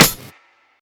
clap_tamb.wav